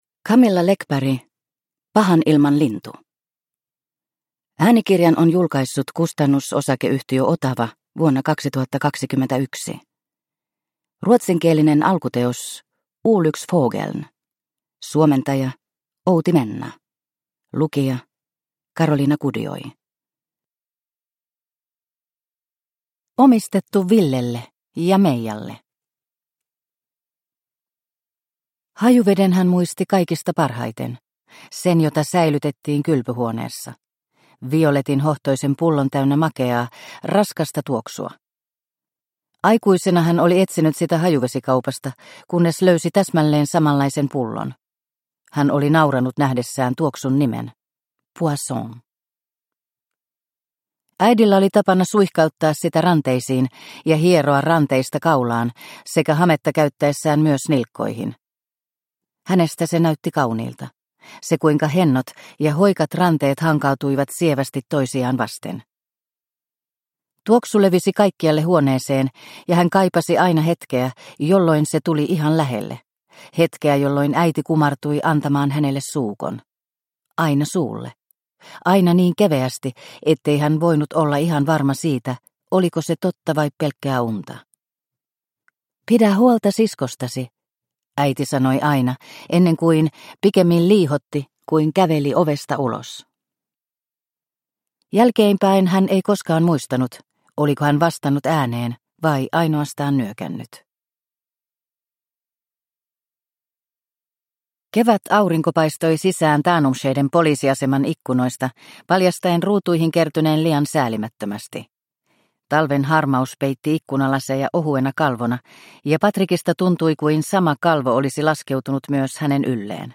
Pahanilmanlintu – Ljudbok – Laddas ner